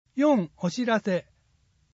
毎月発行している小布施町の広報紙「町報おぶせ」の記事を、音声でお伝えする（音訳）サービスを行っています。 音訳は、ボランティアグループ そよ風の会の皆さんです。